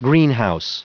Prononciation du mot greenhouse en anglais (fichier audio)
Prononciation du mot : greenhouse